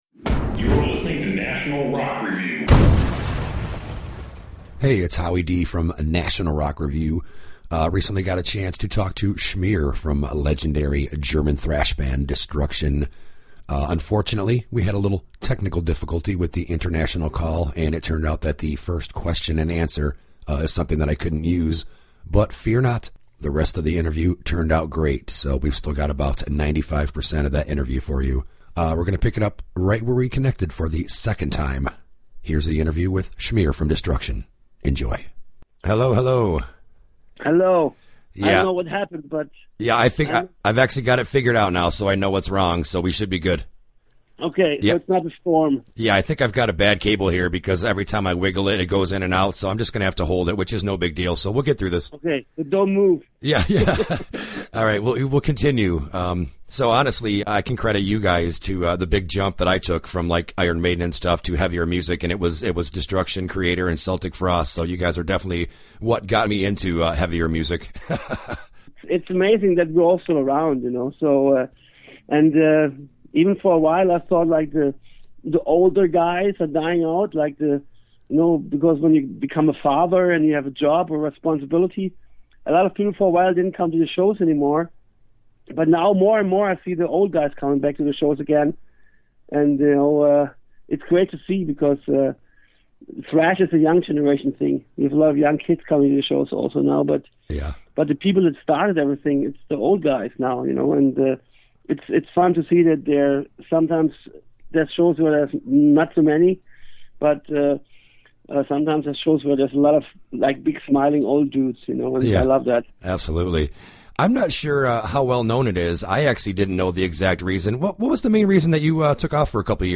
The intrerview with Schmier from Destruction: